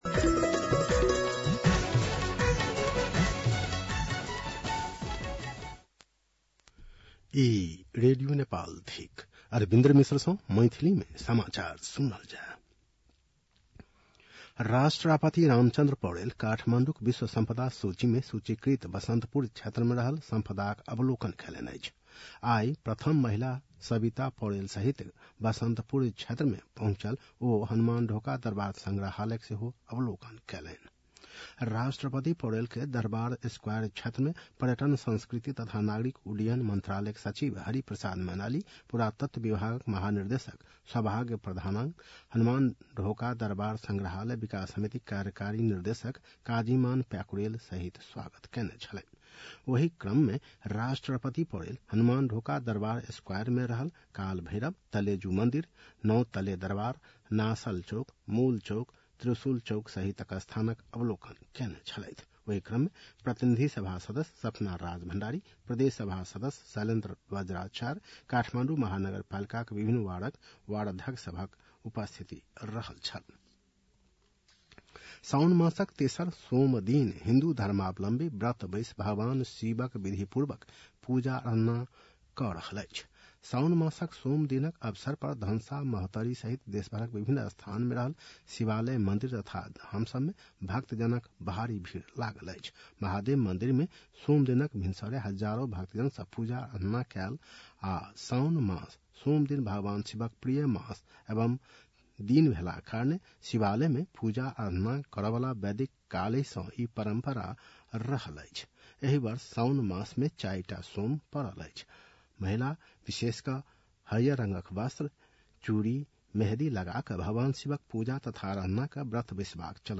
मैथिली भाषामा समाचार : १९ साउन , २०८२